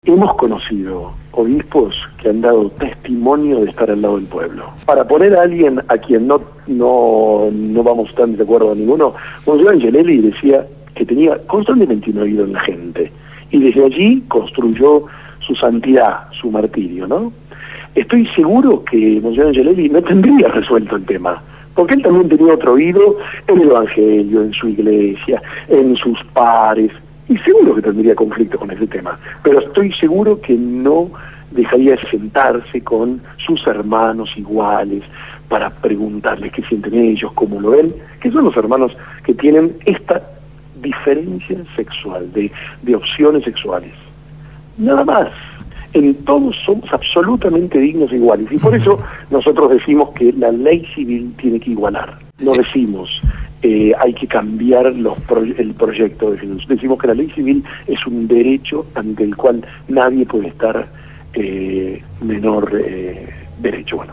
Sacerdote por la opción por los pobres se pronunció en la Gráfica